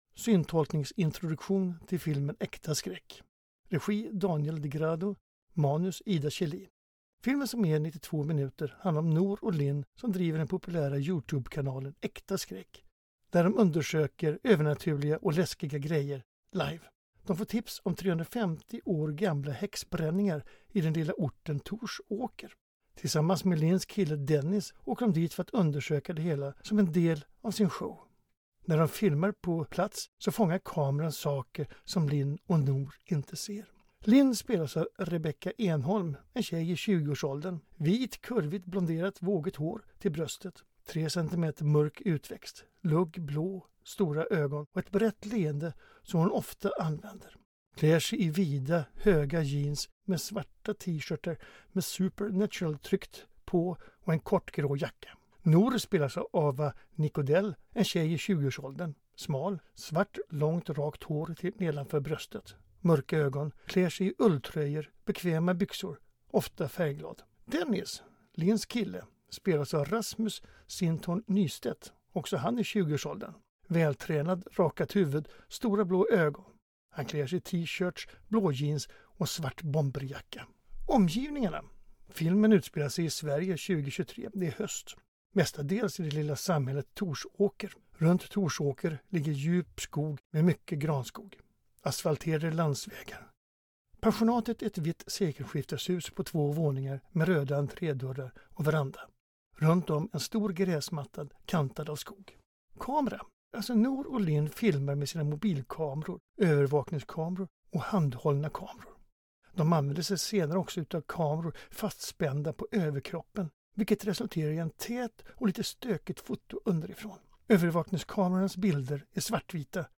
Syntolkningen finns på mobilappen för både bio och TV/streaming.